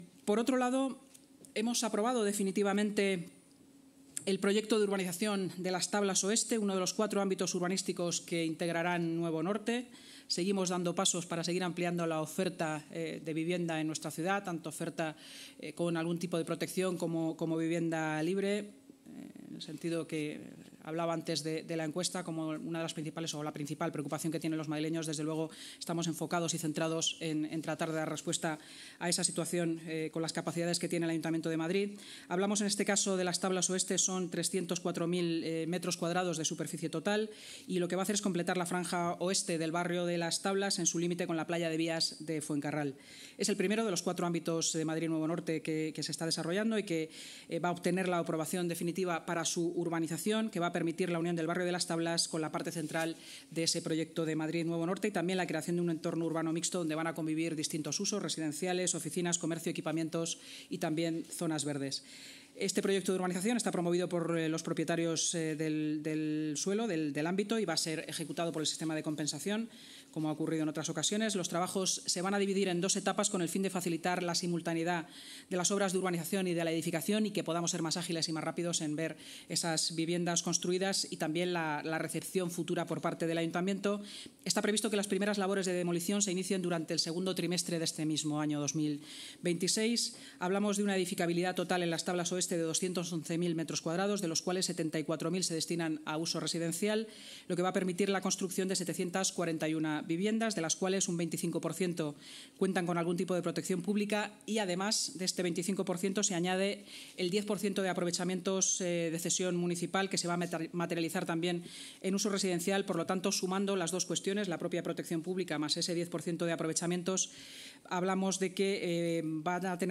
Nueva ventana:La vicealcaldesa de Madrid y portavoz municipal, Inma Sanz